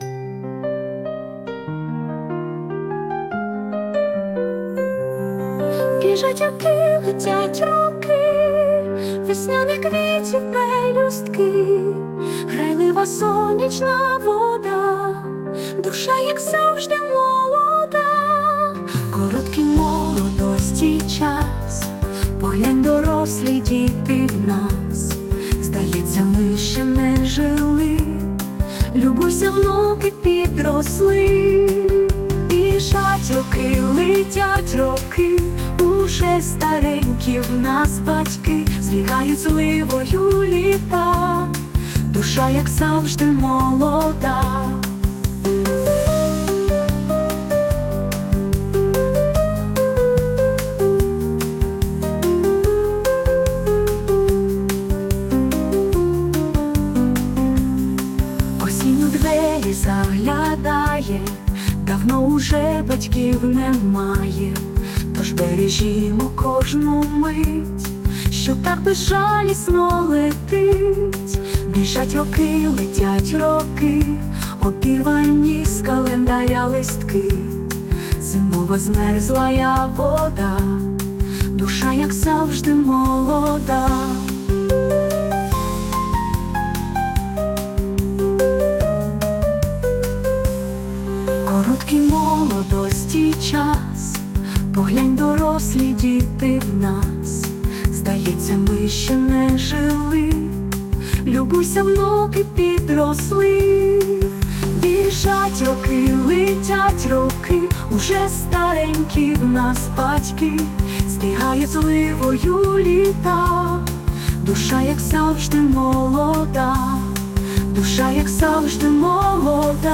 Музична композиція ШІ
ТИП: Пісня
СТИЛЬОВІ ЖАНРИ: Ліричний